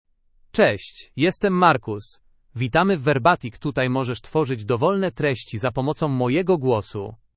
MalePolish (Poland)
MarcusMale Polish AI voice
Voice sample
Male
Marcus delivers clear pronunciation with authentic Poland Polish intonation, making your content sound professionally produced.